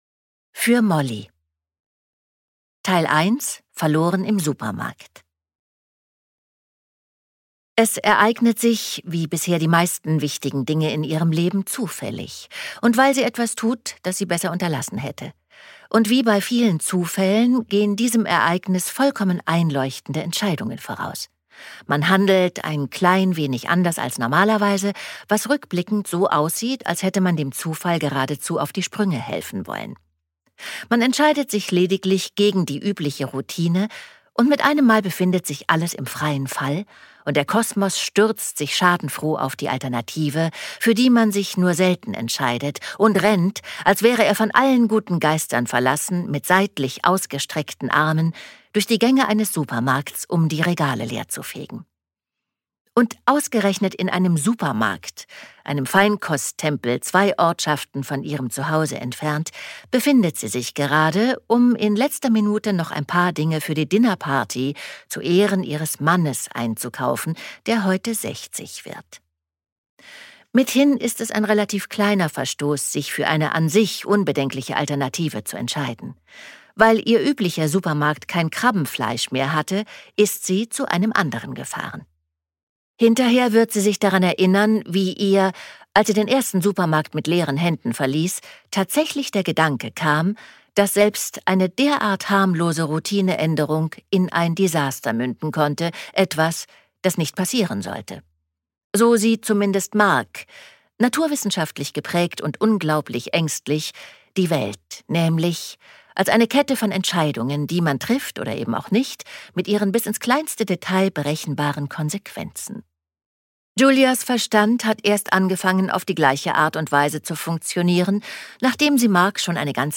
›Genauso wie es immer war‹ gibt es auch als Hörbuch zum Download oder Streamen – gelesen von Andrea Sawatzki.
Ungekürzte Lesung